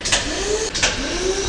00440_Sound_TischAuf.mp3